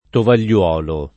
tovagliolo [ toval’l’ 0 lo ]